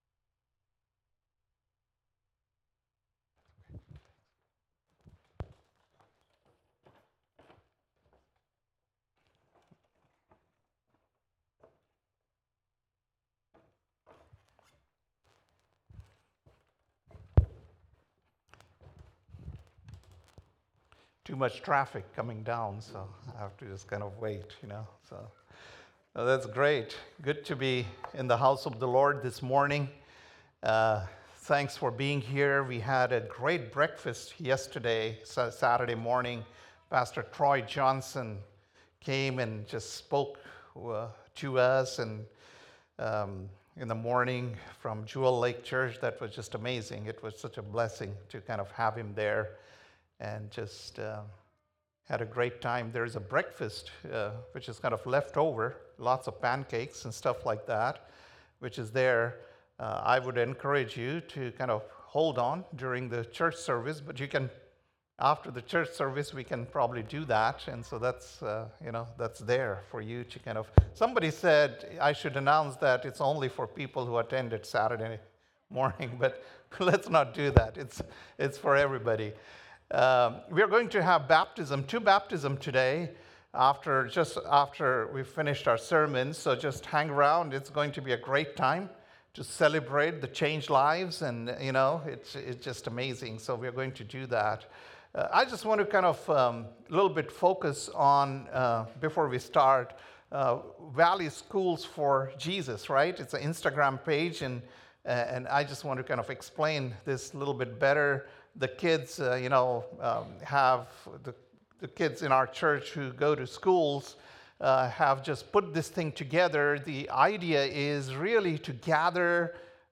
February 16th - Sunday Service - Wasilla Lake Church